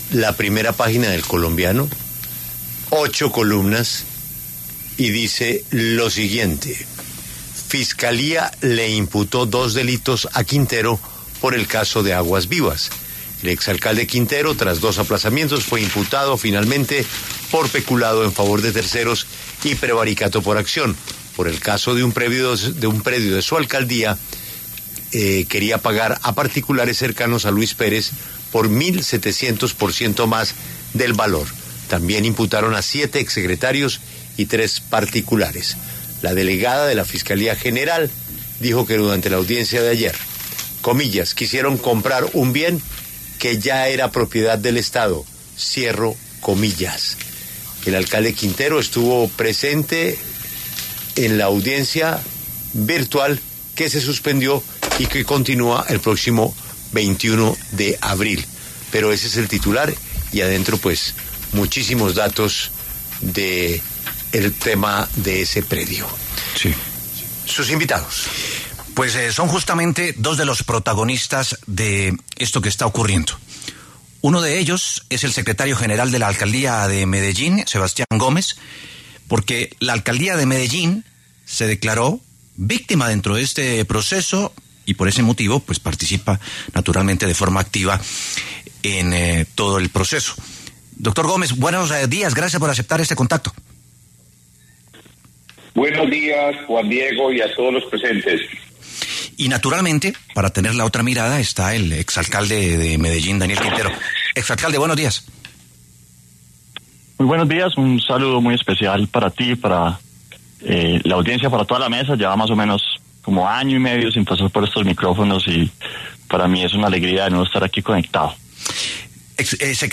En La W se conversó con el secretario general de la Alcaldía de Medellín, Sebastián Gomez, y el exalcalde Daniel Quintero para conocer detalles sobre el caso Aguas Vivas, que ha generado controversia en la ciudad.
Para hablar sobre el tema, pasaron por los micrófonos de La W el secretario general de la Alcaldía de Medellín, Sebastián Gomez, y el exalcalde de la ciudad, Daniel Quintero.